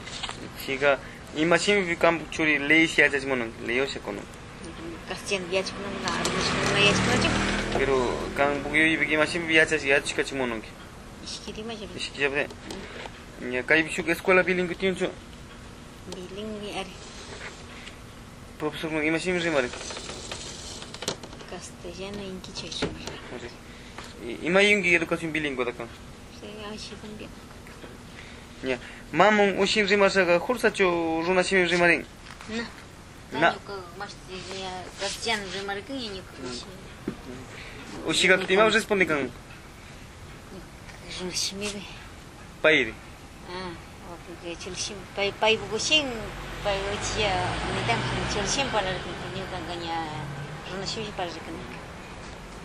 Entrevistas - San Cristóbal